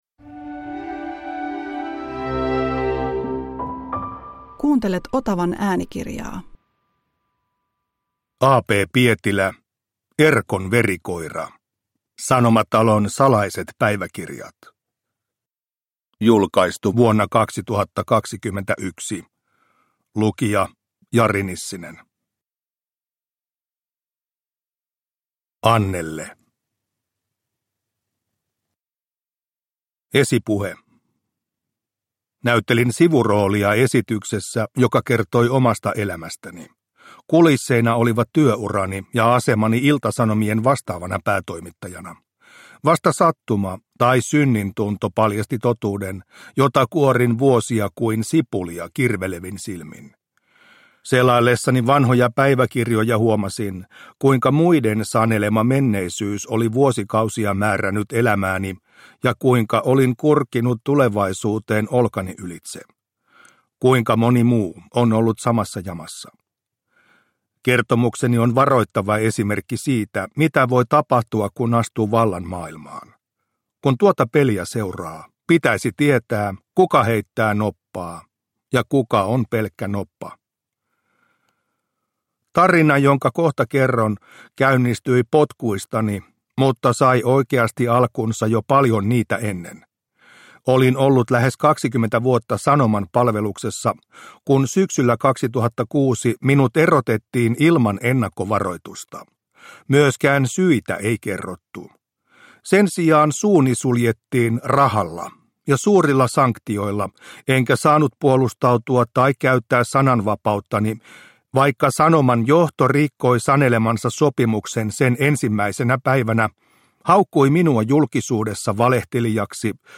Erkon verikoira – Ljudbok – Laddas ner